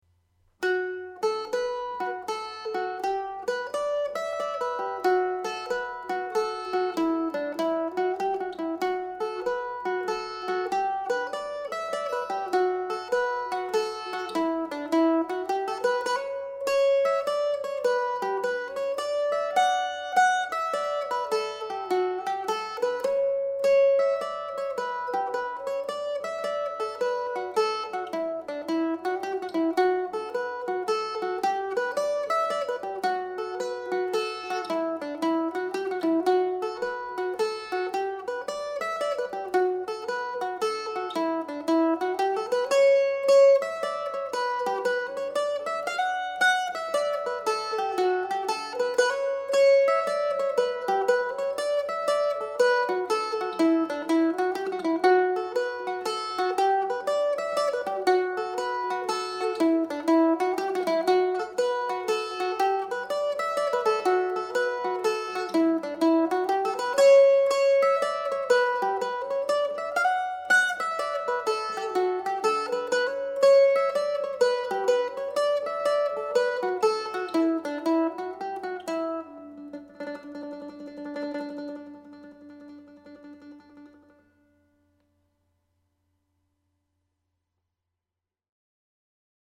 Slip Jig (D Major)
Listen to the tune played at slip jig speed
The-Snowny-Path_normal_speed.mp3